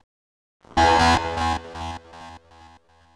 Syncer Low.wav